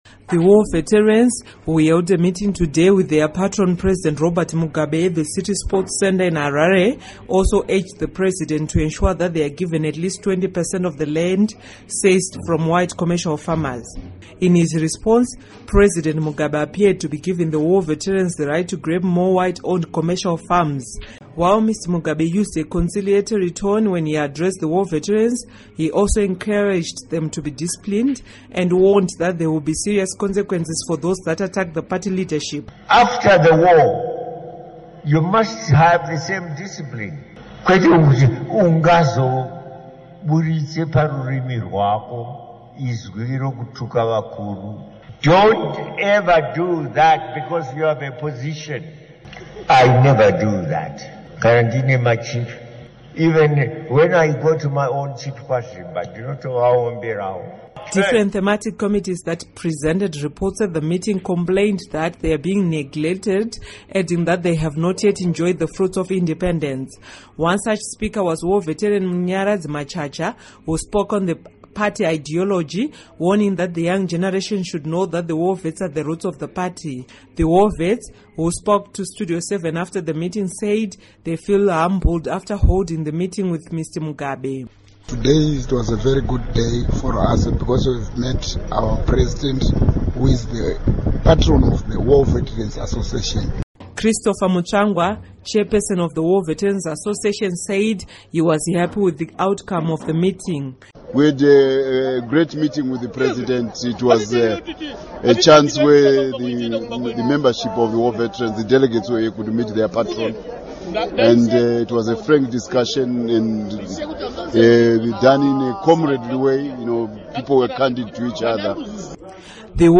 War veterans held a meeting with President Robert Mugabe at the City Sports Center.